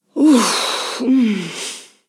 Mujer resoplando
resoplar
Sonidos: Acciones humanas
Sonidos: Voz humana